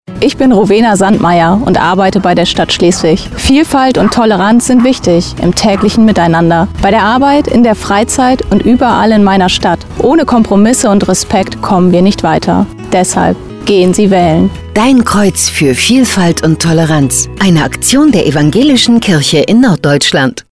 Um möglichst viele Menschen zu erreichen, haben wir Radiospots in norddeutschen Sendern geschaltet, die wir in Kooperation mit dem Evangelischen Presseverband Nord produziert haben. Sechs Menschen aus unserer Landeskirche haben mitgemacht und ein „Testimonial“ eingesprochen.